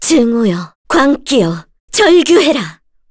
FreyB-Vox_Skill2b_kr.wav